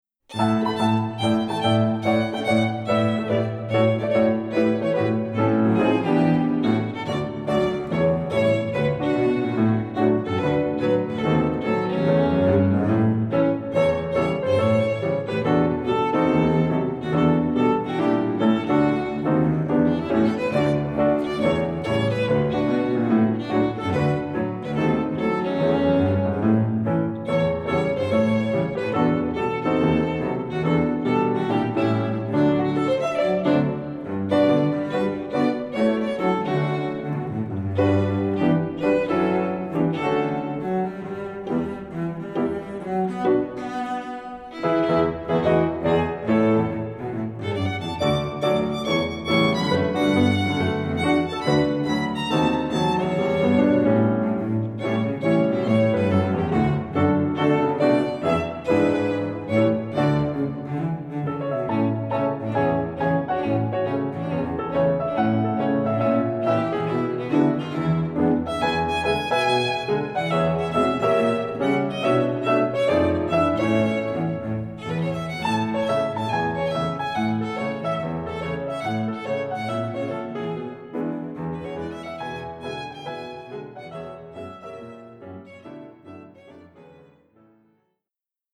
Voicing: Piano Trio